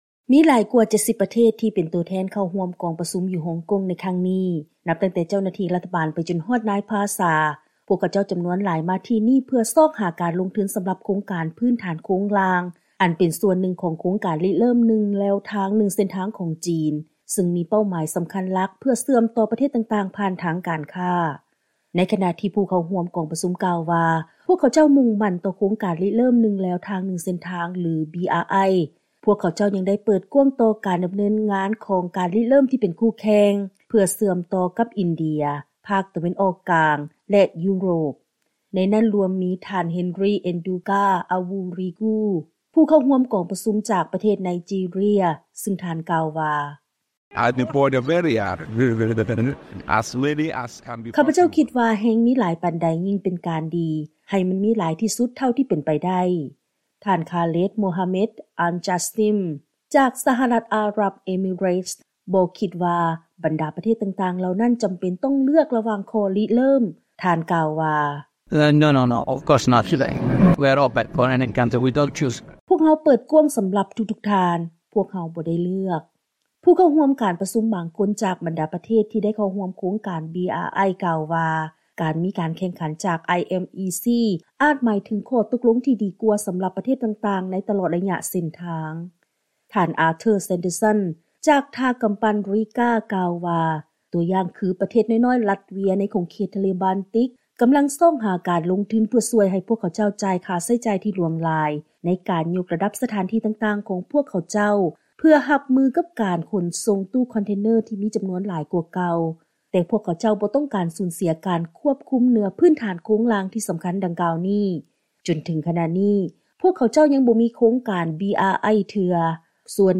ເຊີນຟັງລາຍງານກ່ຽວກັບ ການຈັດກອງປະຊຸມສຸດຍອດໂຄງການ BRI ໃນໂອກາດຄົບຮອບ 10 ປີນຶ່ງແລວທາງນຶ່ງເສັ້ນທາງຂອງ ຈີນ.